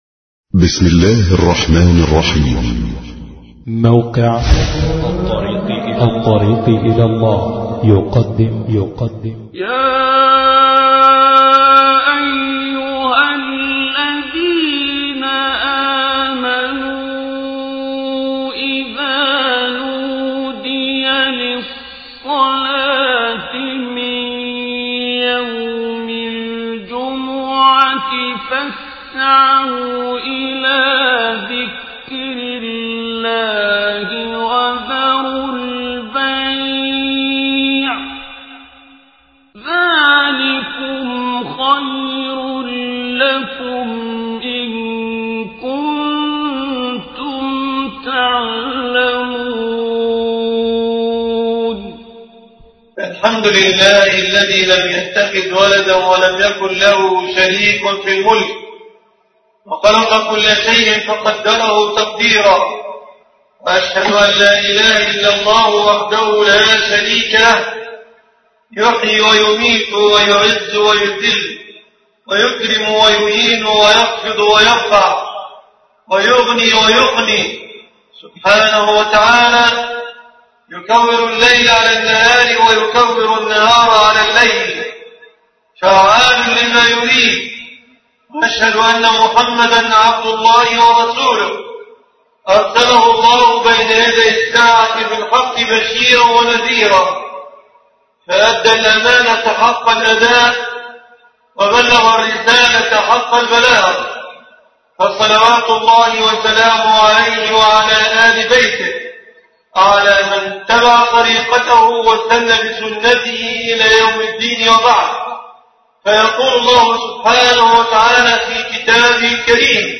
رمضان منهج حياه (مجمع الرحمن طلخا 8رمضان1433